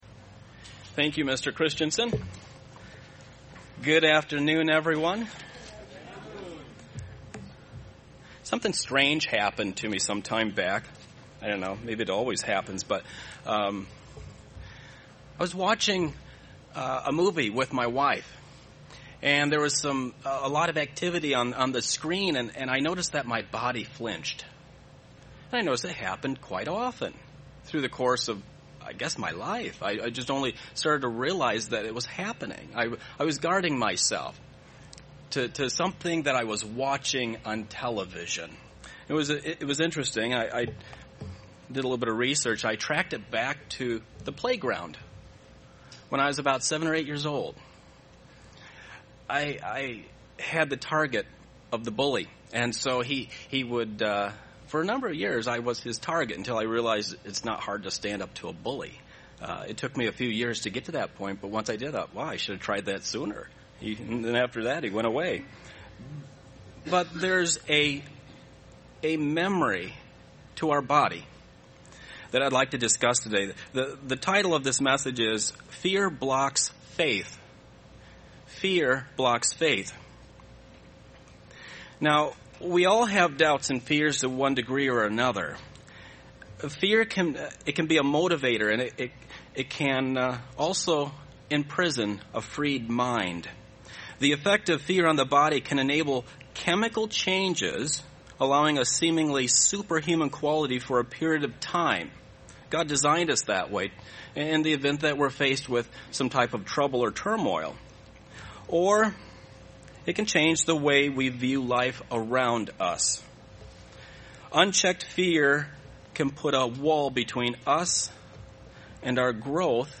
UCG Sermon Studying the bible?
Given in Beloit, WI